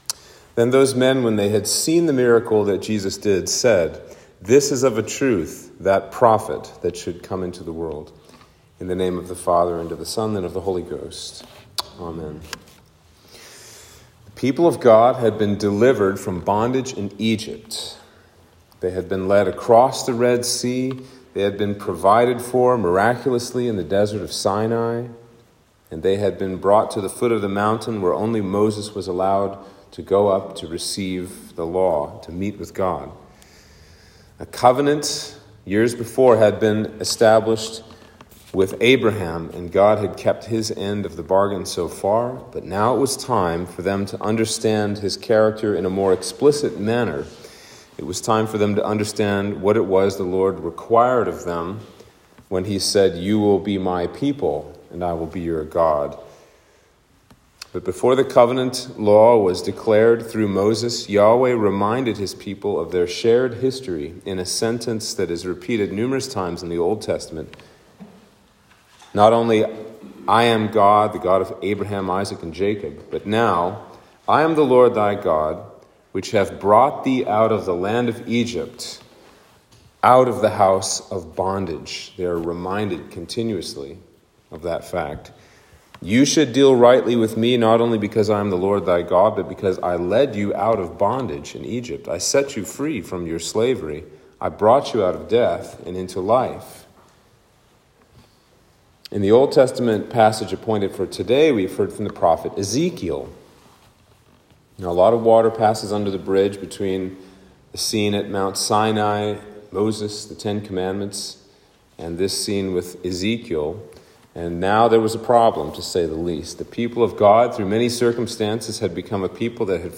Sermon for Lent 4